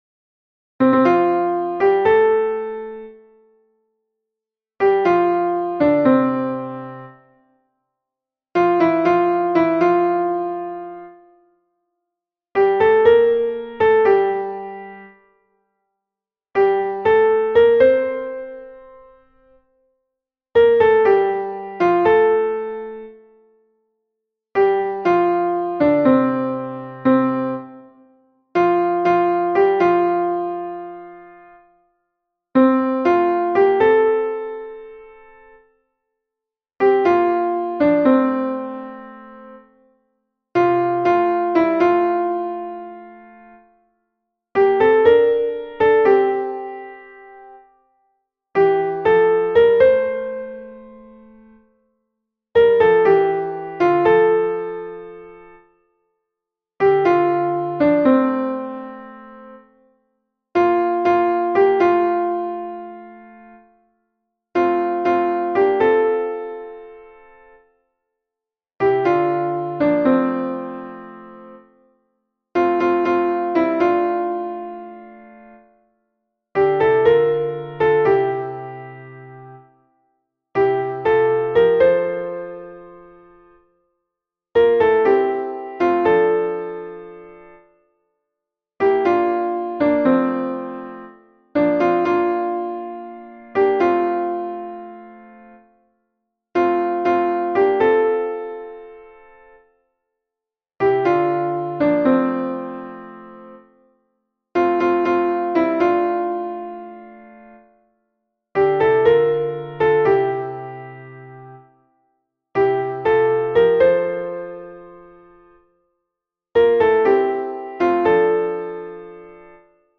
MP3 version piano
Soprano Piano